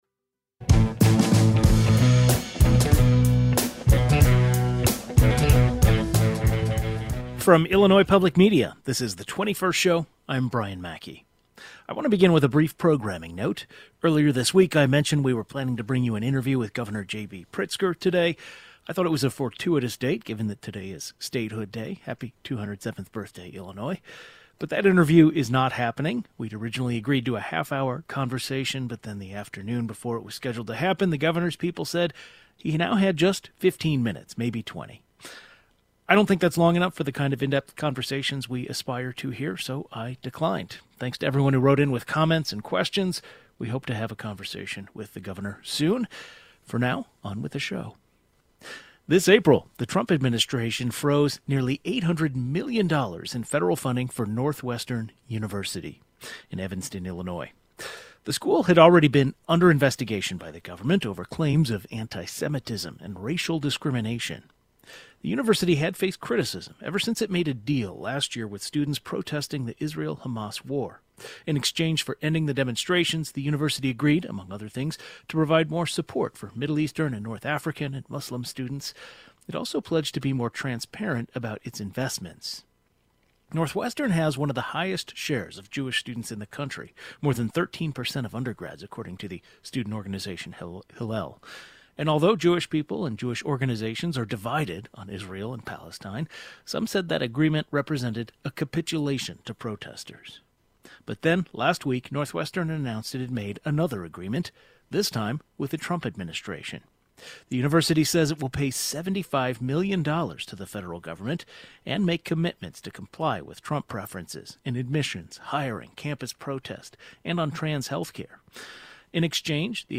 An education reporter and professors specializing in law and government policy share their analysis of the agreement.